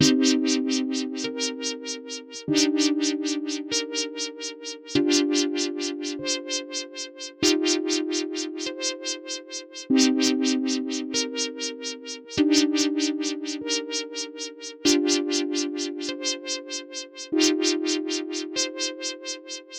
标签： 97 bpm Trap Loops Synth Loops 3.33 MB wav Key : D Cubase
声道立体声